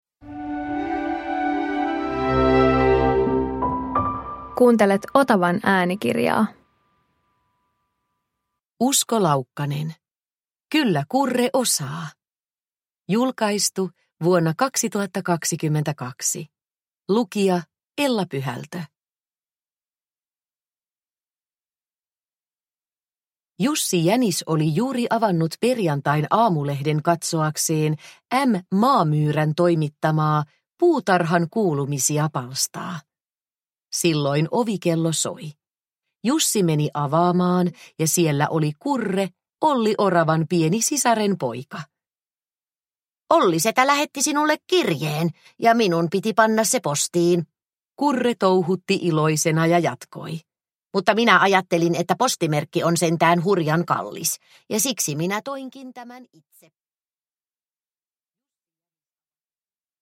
Kyllä Kurre osaa – Ljudbok – Laddas ner